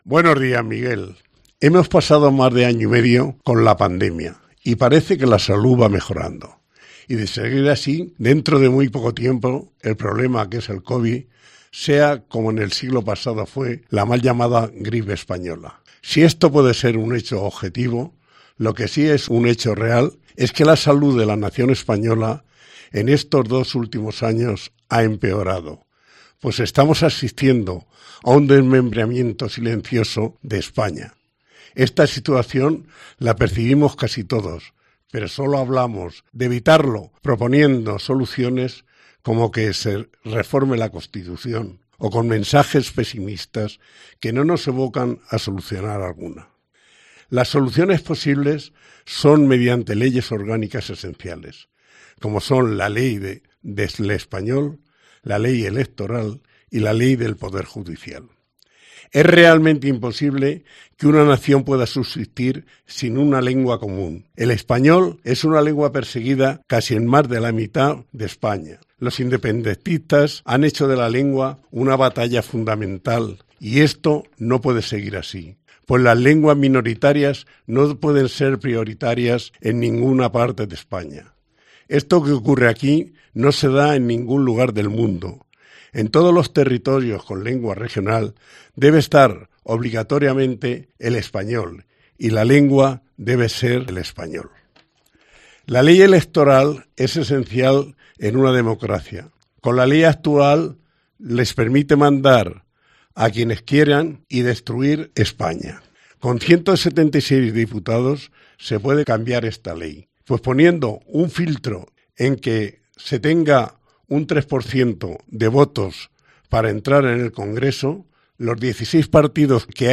Opinión en formato radio